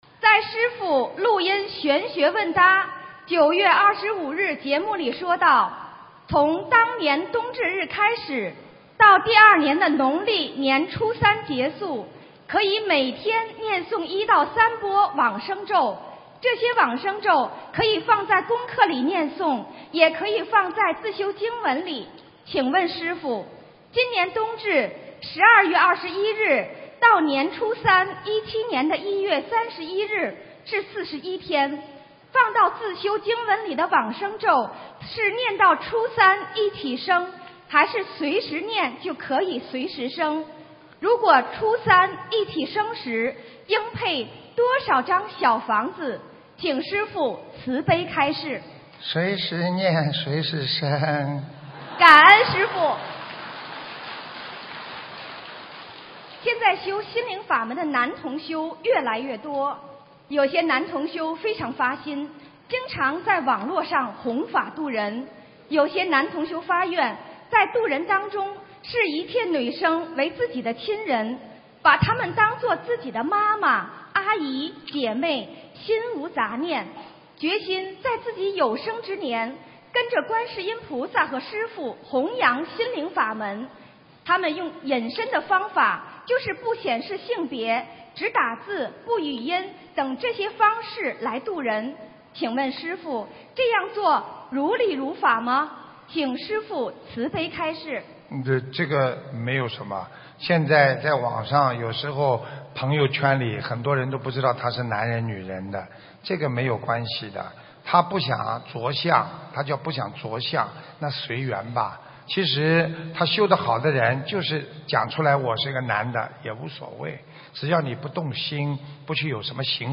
台北世界佛友见面会共修组提问161003